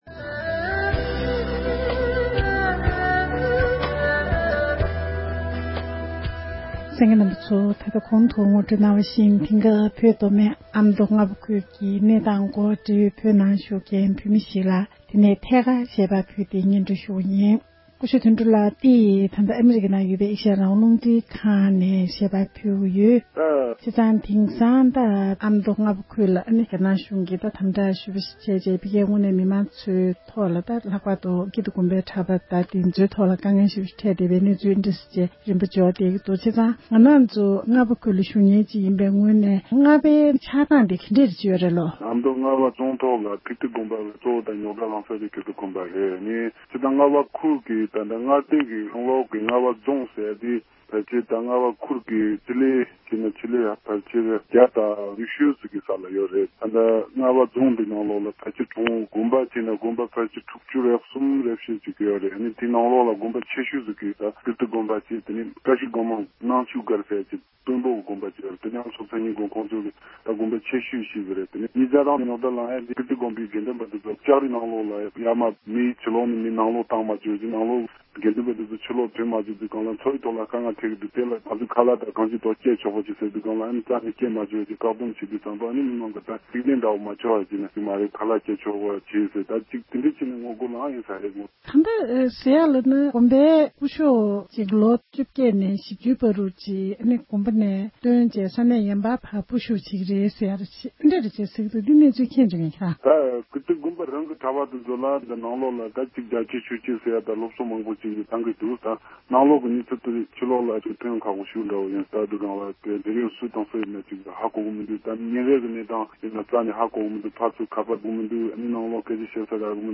བོད་ནས་བོད་མི་ཞིག་གིས་བོད་ཨ་མདོ་རྔ་པ་ཁུལ་གྱི་གནས་སྟངས་དང་འབྲེལ་བའི་སྐོར་གསུངས་བ།